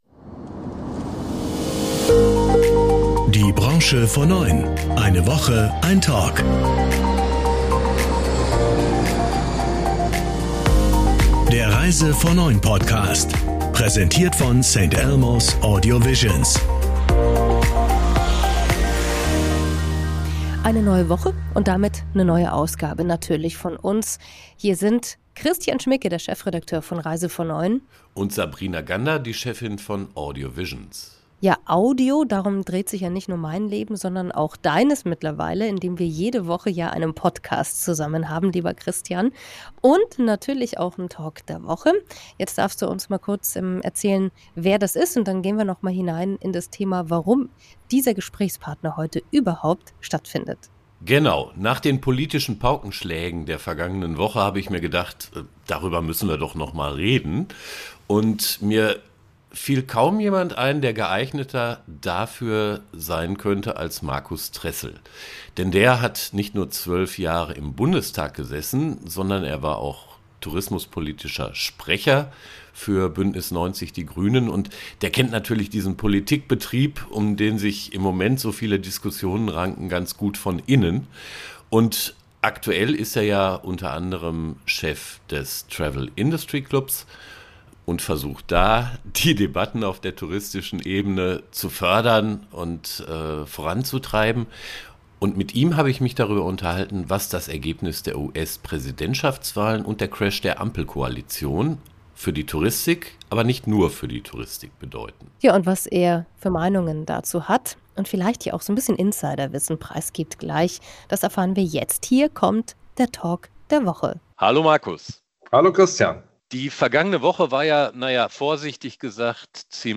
Nach den US-Wahlen und dem Platzen der Ampel-Regierung in Deutschland stehen politisch turbulente Zeiten an. Im Reise vor9 Podcast sprechen wir mit dem ehemaligen Bundestagsabgeordneten und heutigen TIC-Präsidenten Markus Tressel darüber, was das auch, aber nicht nur für die Touristik bedeutet.